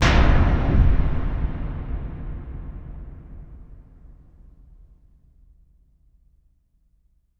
LC IMP SLAM 5A.WAV